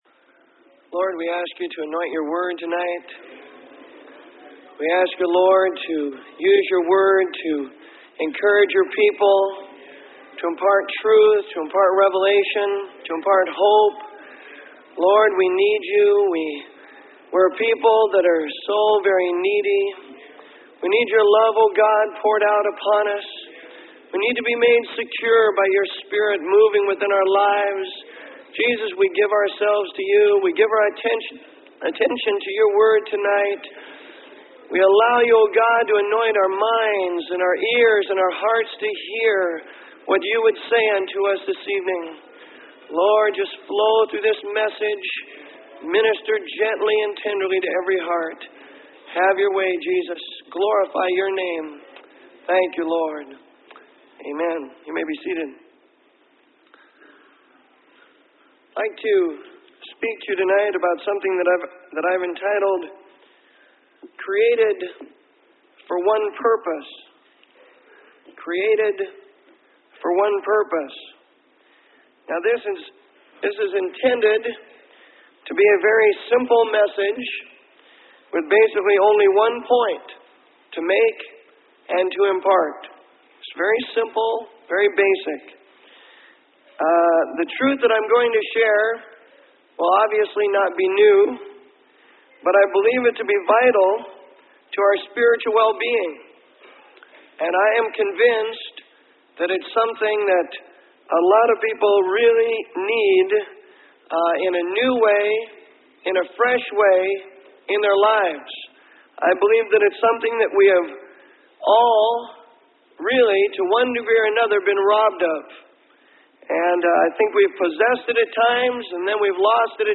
Sermon: Created For One Purpose.